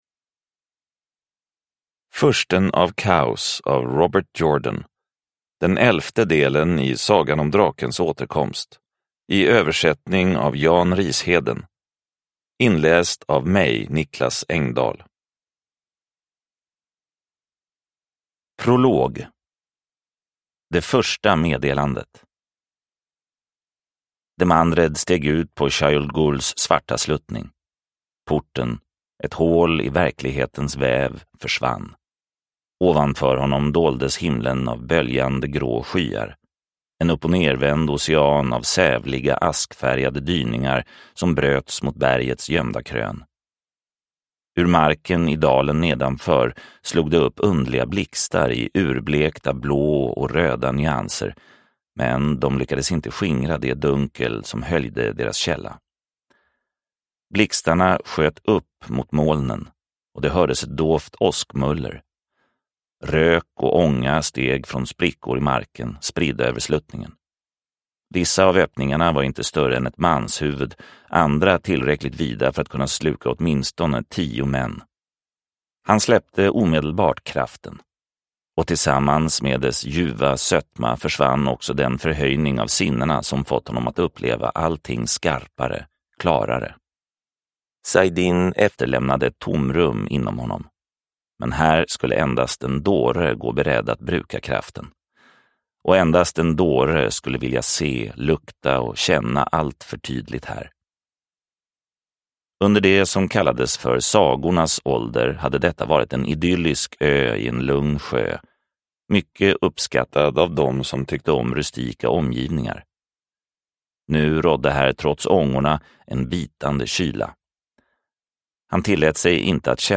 Fursten av Kaos – Ljudbok – Laddas ner